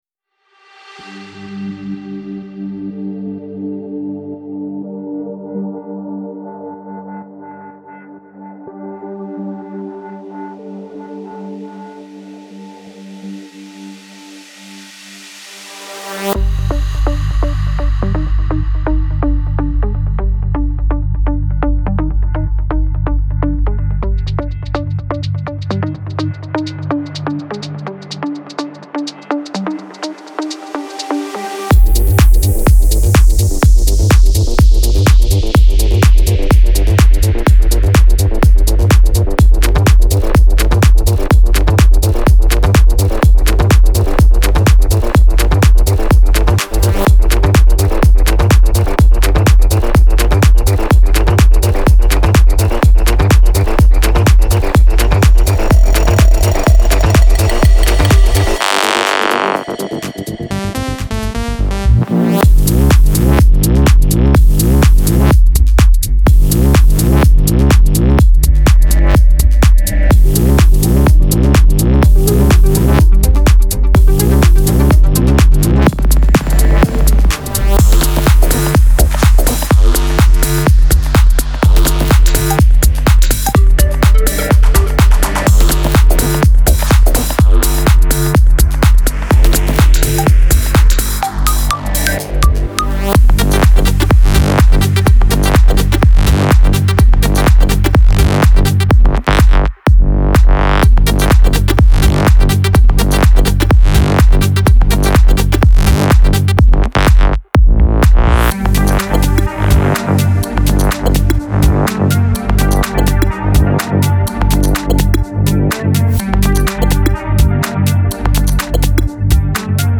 Collection of techno samples and loops
– 130 Loops (125 BPM)
MP3 DEMO